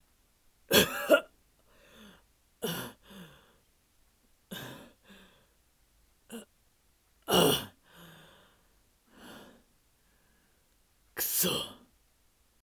声劇2